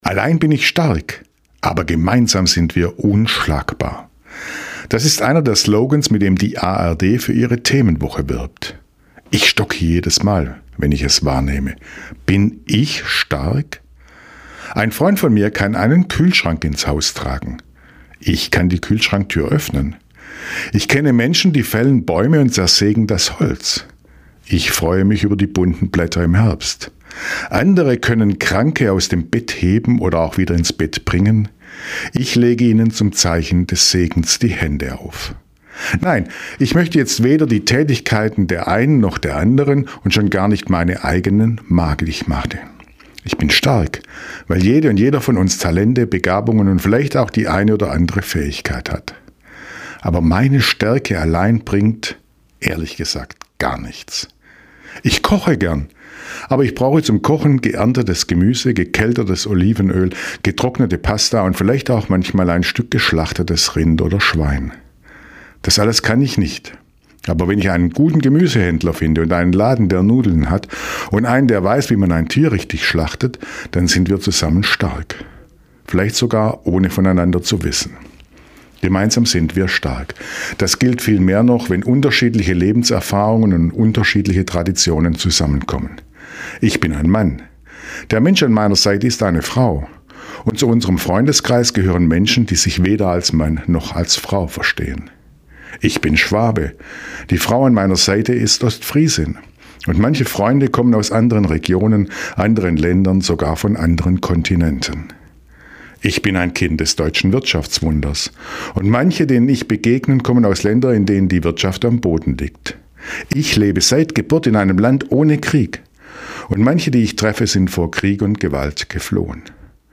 Radioandacht vom 28. Oktober